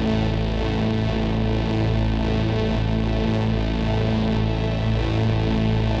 Index of /musicradar/dystopian-drone-samples/Non Tempo Loops
DD_LoopDrone5-A.wav